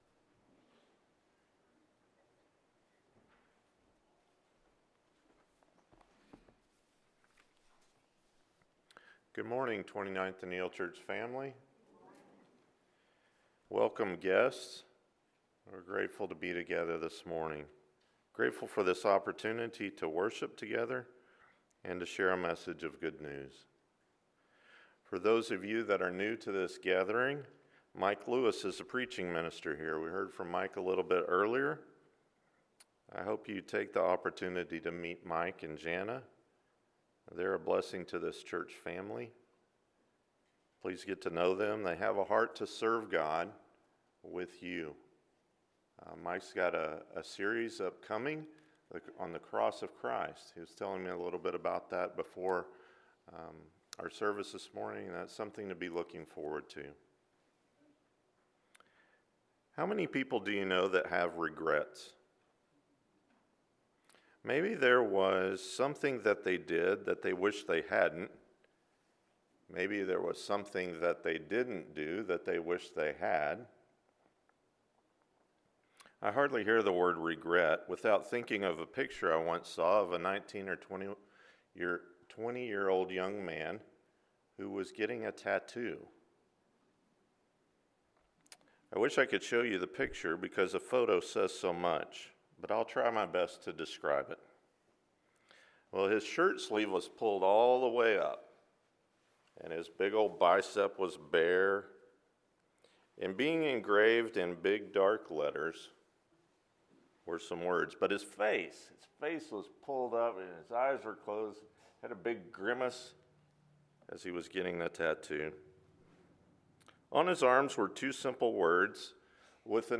Jesus’ Sermon on the Mount: Prayerfully – Matthew 5-7 – Sermon — Midtown Church of Christ
JesusSermonOnTheMount-Prayerfully-Matthew-5-7-AM.mp3